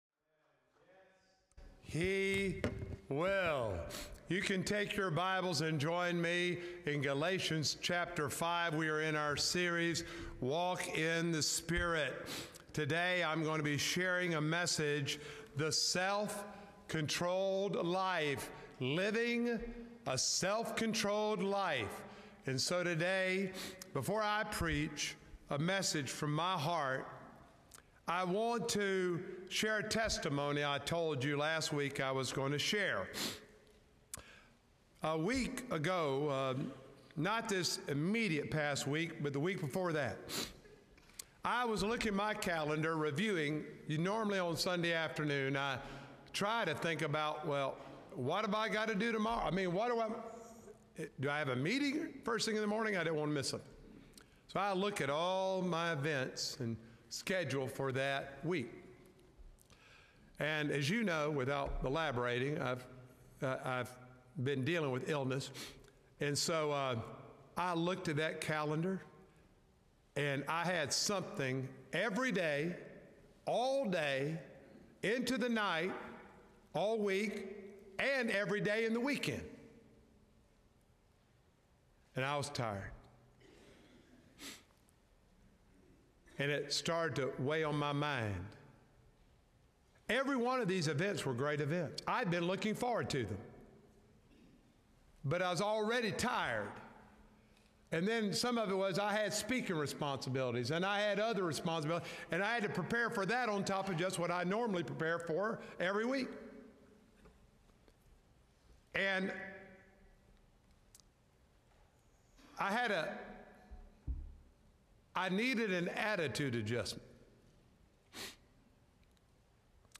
Sermons
March-9-2025-Sermon-Audio.mp3